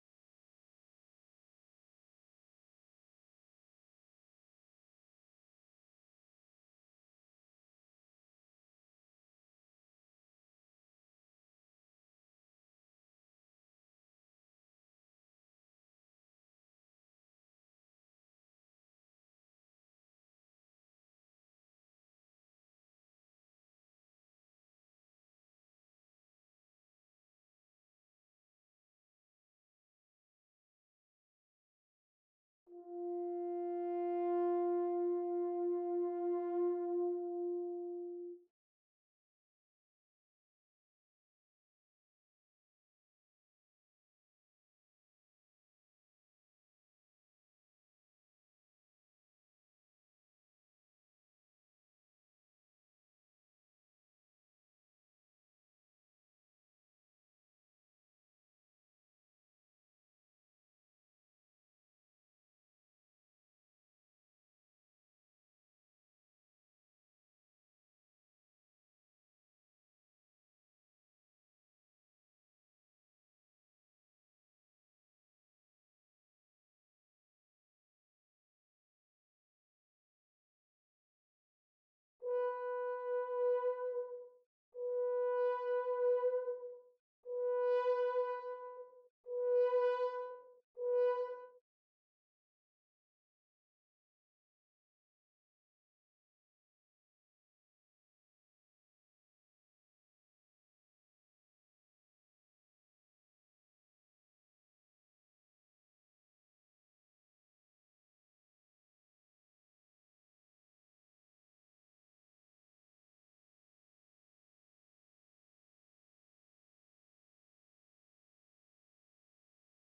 11.  (Horn/Normal)
Holst-Mars-16-Horn_5.mp3